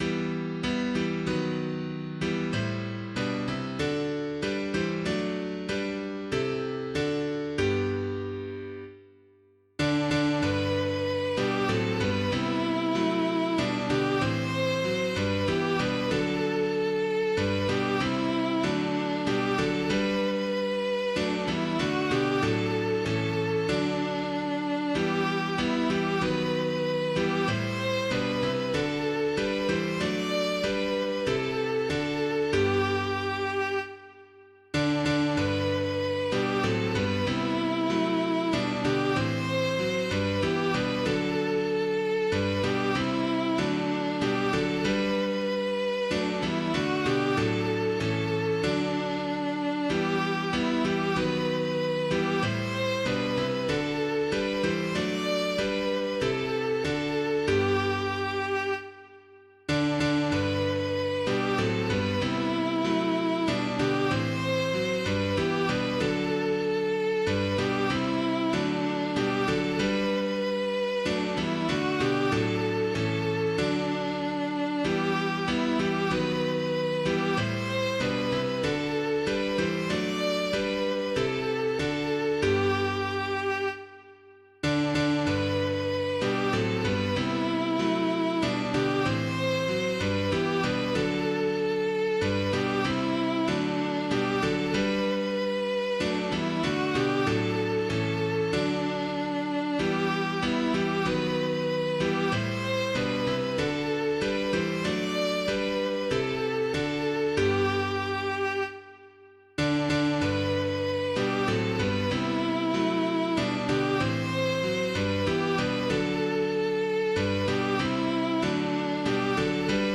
For the Beauty of the Earth [Pierpoint - LUCERNA LAUDONIAE] - piano.mp3